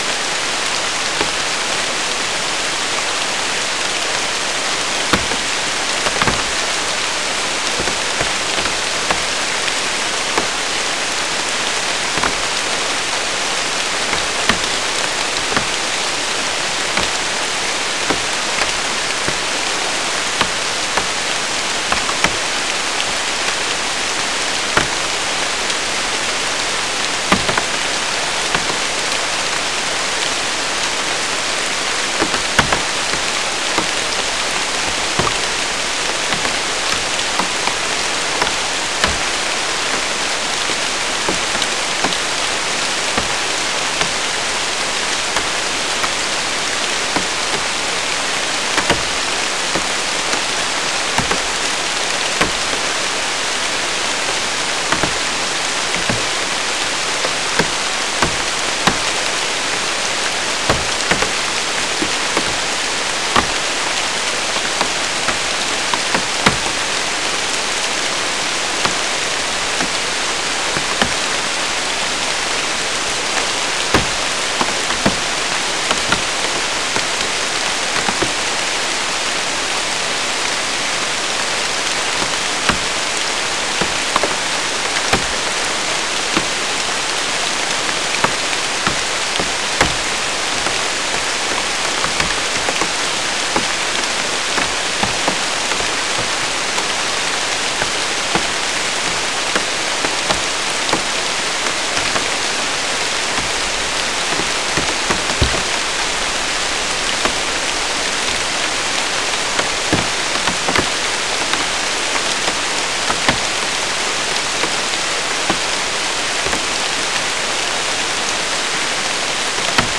Soundscape Recording Location: South America: Guyana: Sandstone: 2
Recorder: SM3